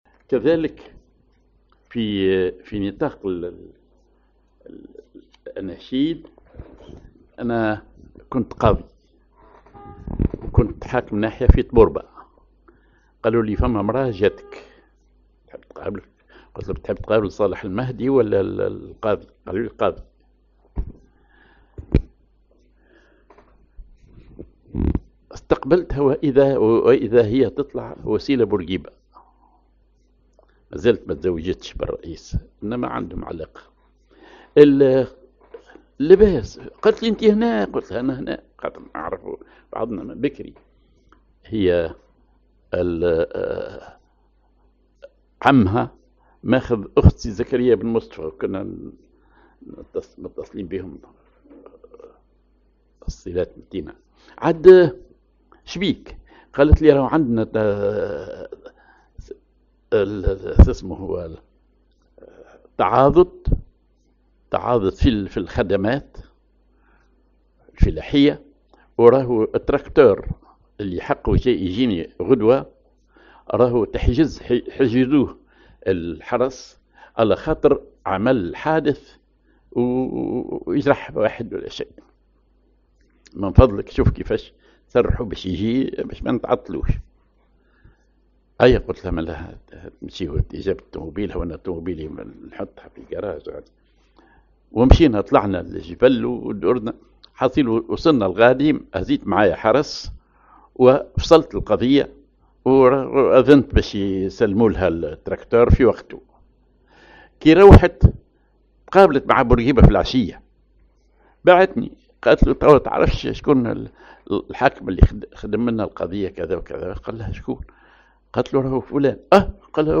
Maqam ar مزموم على درجة الراست
genre أغنية